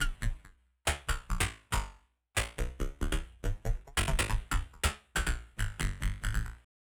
Abstract Rhythm 10.wav